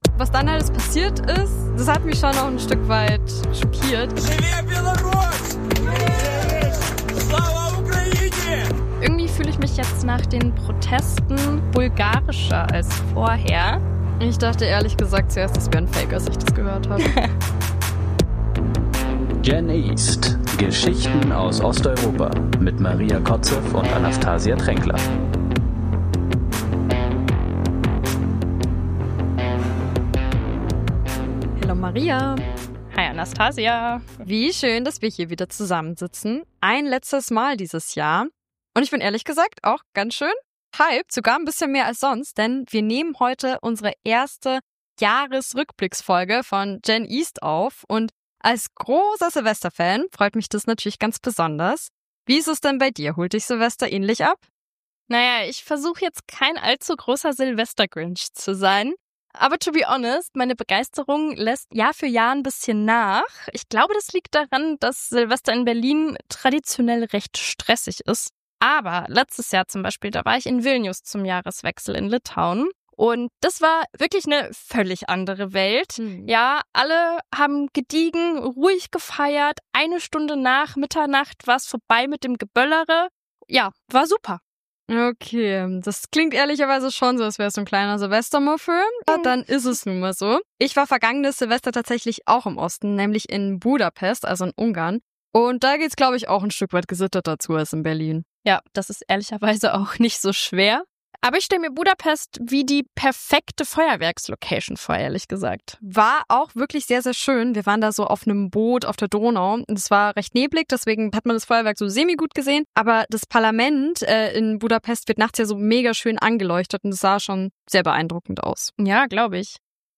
Wir sprechen über die großen Themen dieses Jahres: Gen-Z-Proteste gegen Korruption in Serbien und Bulgarien, den Krieg in der Ukraine, die Präsidentenwahl in Polen und die Frage, ob im Südkaukasus nun wirklich Frieden herrscht. Dazwischen werfen wir einen Blick auf skurrile Schlagzeilen – von der KI-Ministerin in Albanien bis zum russischen Anti-Eurovision-Contest. Ihr hört diesmal teils bekannte, teils neue Stimmen